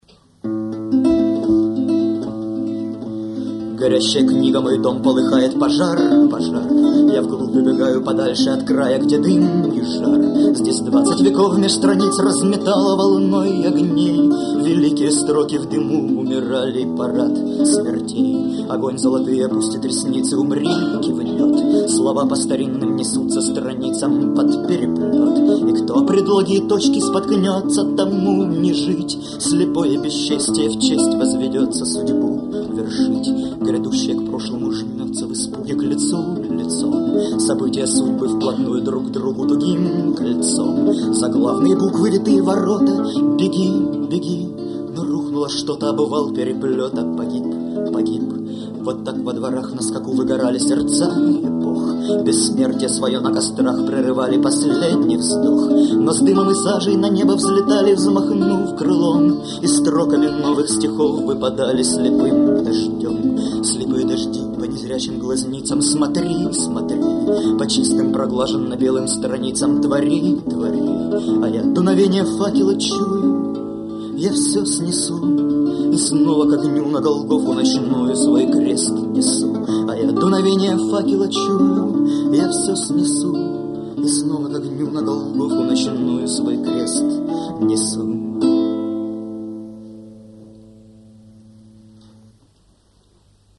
На данной странице представлена Киевская авторская песня.